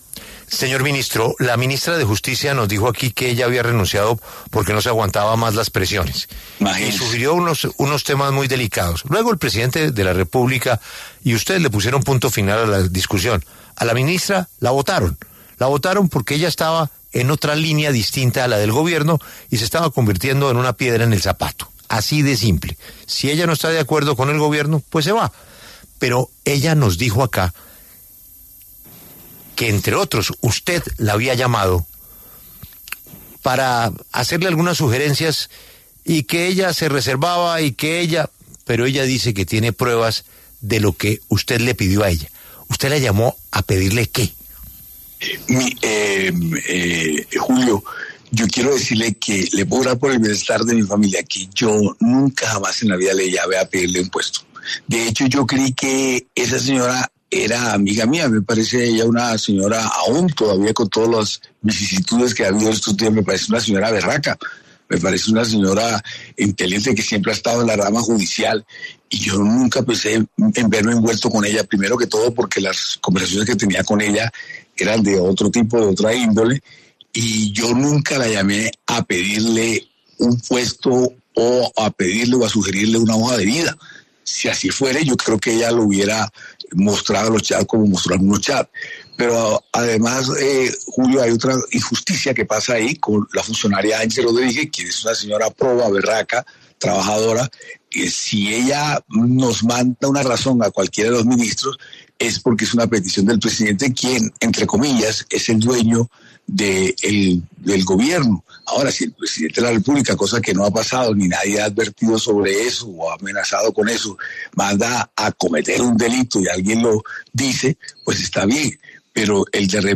El ministro del Interior, Armando Benedetti, conversó con La W, con Julio Sánchez Cristo, a propósito de las declaraciones que dio a este medio Ángela María Buitrago, exministra de Justicia, quien aseguró que él y la directora del Dapre, Angie Rodríguez, la llamaban para pedirle puestos.